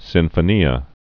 (sĭnfə-nē-ə)